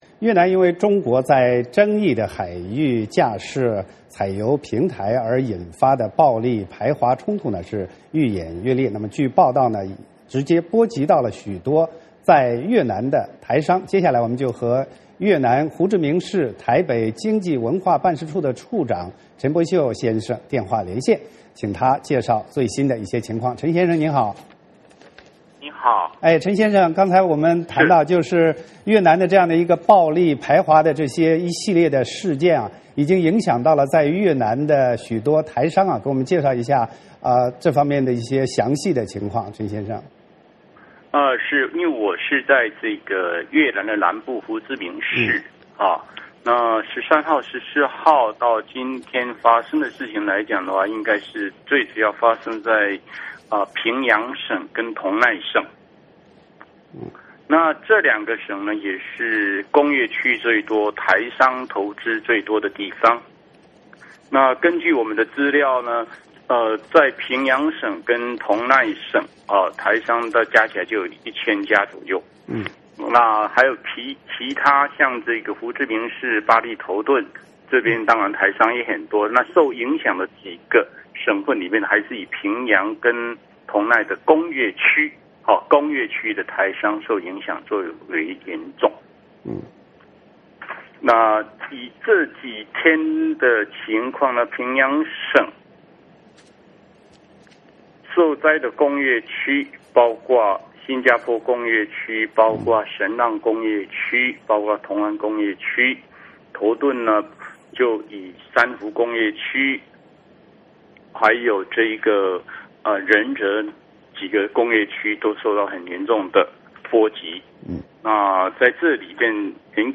我们和越南胡志明市台北经济文化办事处处长陈柏秀先生电话连线,请他介绍最新状况。